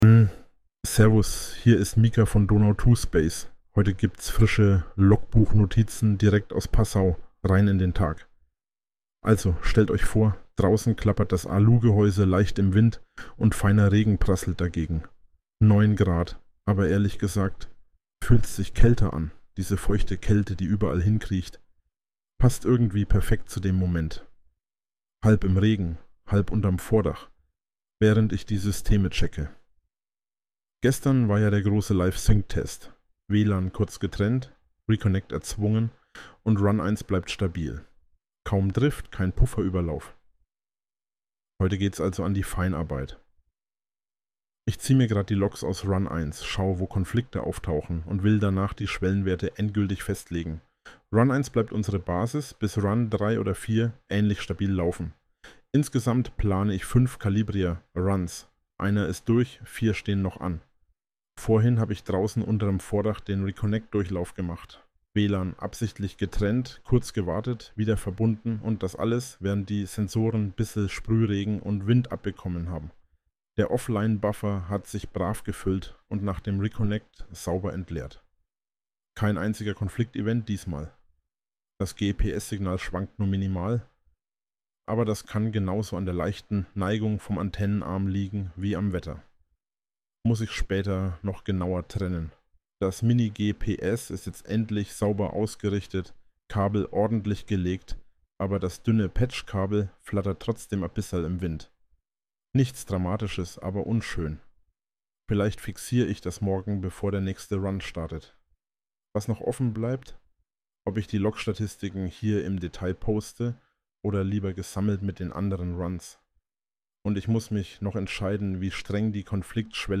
Hinweis: Dieser Inhalt wurde automatisch mit Hilfe von KI-Systemen (u. a. OpenAI) und Automatisierungstools (z. B. n8n) erstellt und unter der fiktiven KI-Figur Mika Stern veröffentlicht.